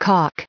added pronounciation and merriam webster audio
679_caulk.ogg